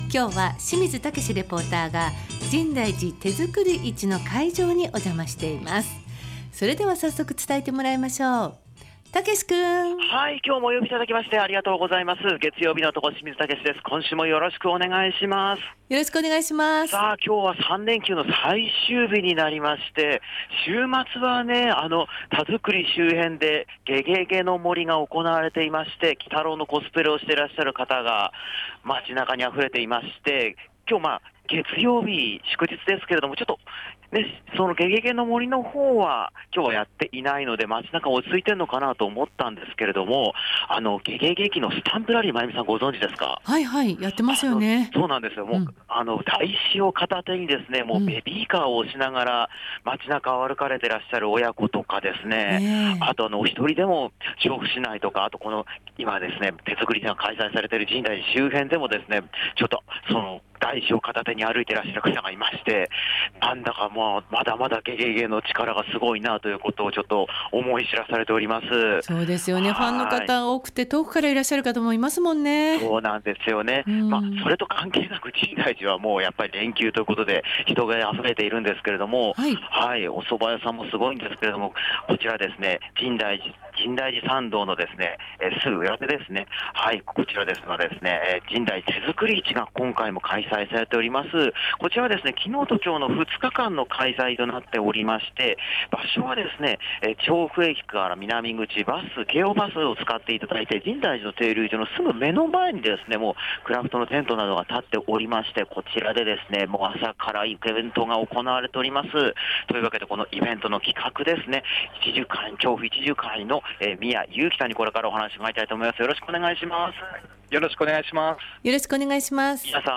快晴の秋晴れの空の下からお届けした街角レポートは、深大寺から「深大寺手作り市」のレポートです！